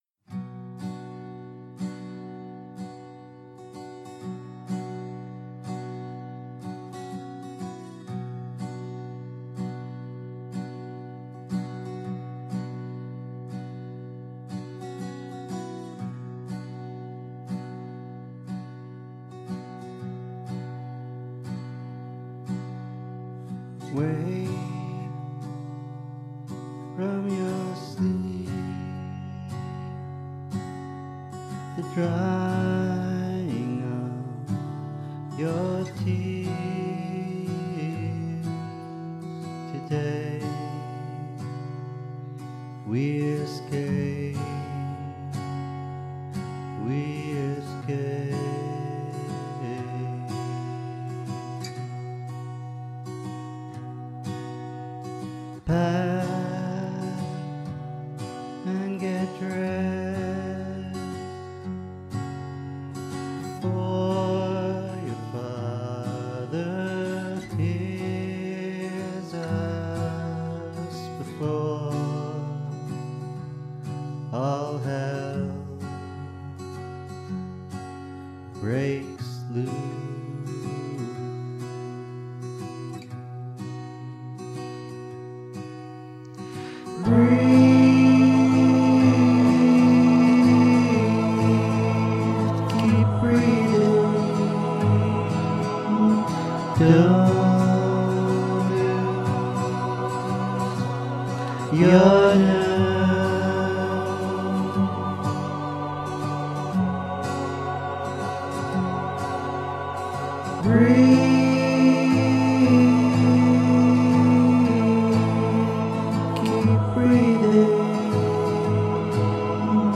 Tag: vocals